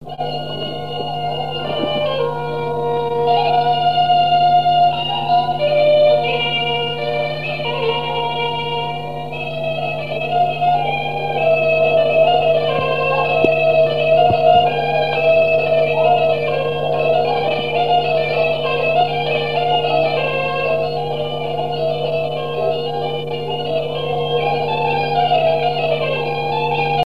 danse : dérobées
Pièce musicale inédite